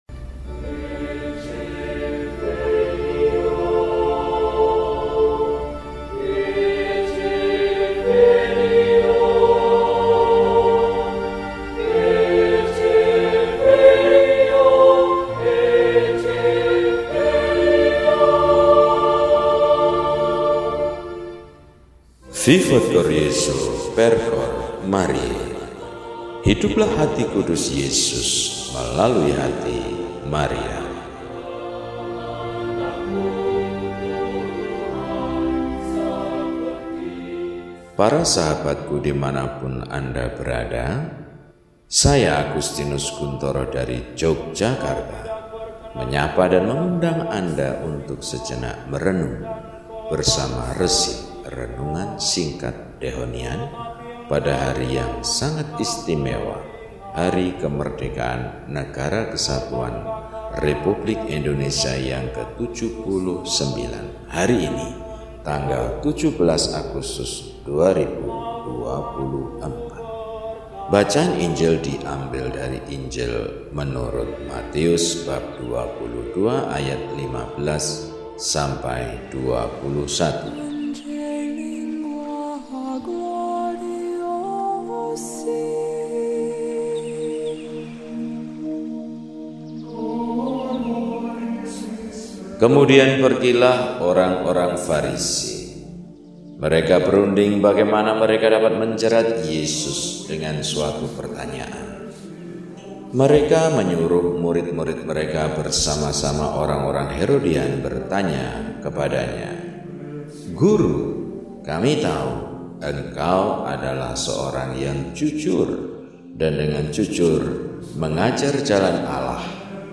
Sabtu, 17 Agustus 2024 – Hari Raya Kemerdekaan Republik Indonesia – RESI (Renungan Singkat) DEHONIAN